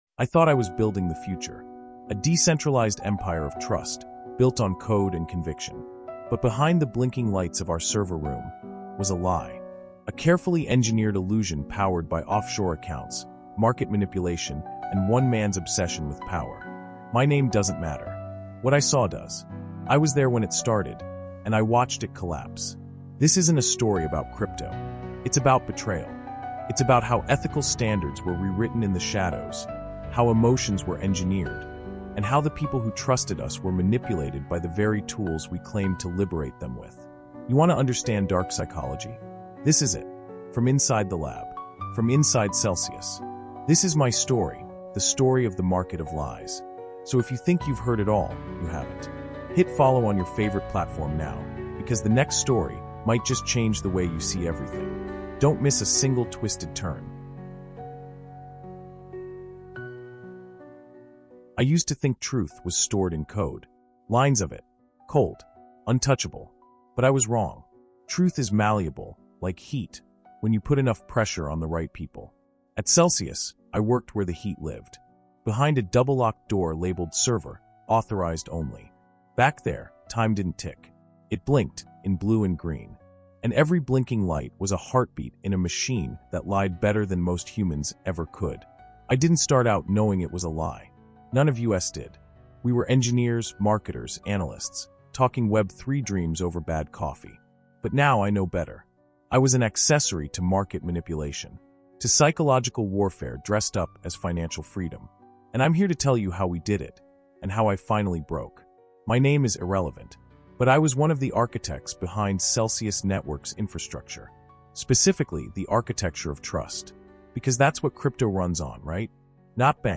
Market of Lies is a true crime manipulation thriller that exposes the psychological warfare and social engineering tactics behind the fall of Celsius Network. Told in raw, unfiltered first-person voice by a former insider, this immersive series pulls you deep into the dark psychology of corporate deception, exploring how communication, control, persuasion, and body language were weaponized to build a billion-dollar illusion.